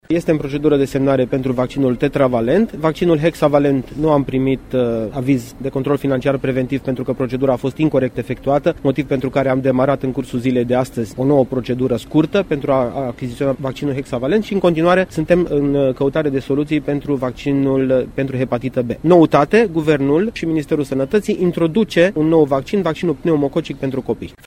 Anunțul a fost făcut azi de ministrul Sănătății, Florian Bodog. Acesta explică de ce a fost nevoie de noua procedură.